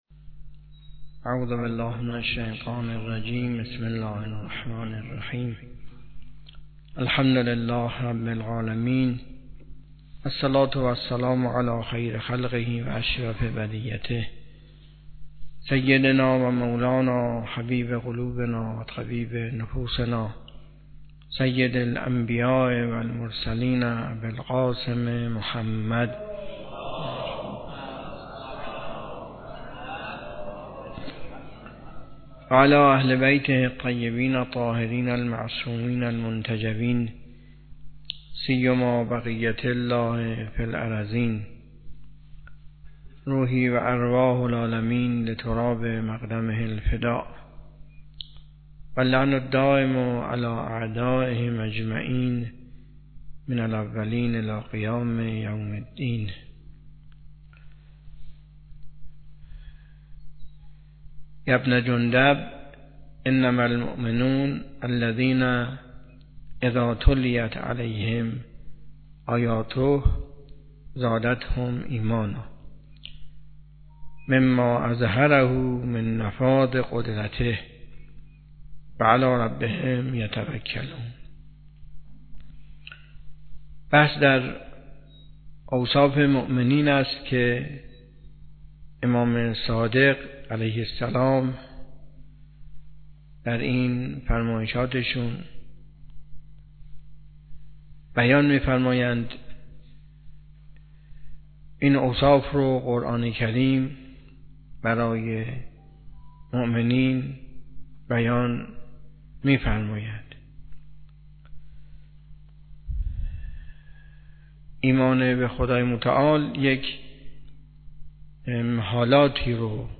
حوزه علمیه معیر تهران
درس اخلاق